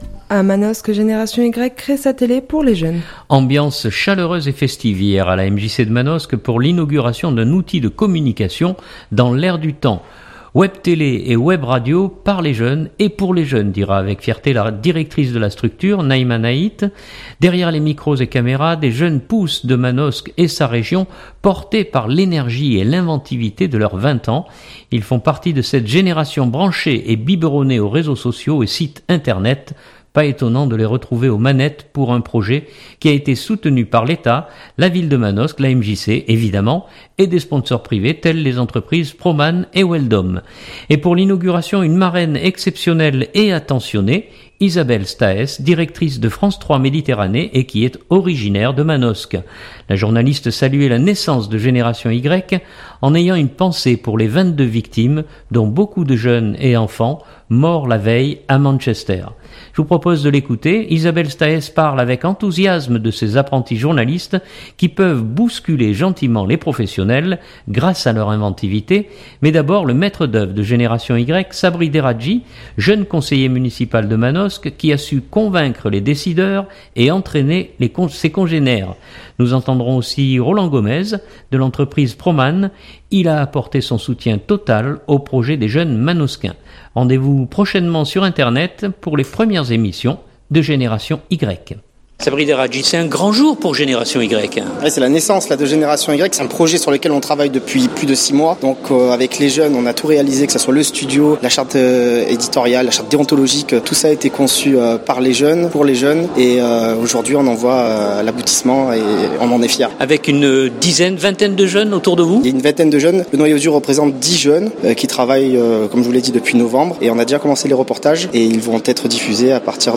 Ambiance chaleureuse et festive hier à la MJC de Manosque pour l’inauguration d’un outil de communication dans l’air du temps.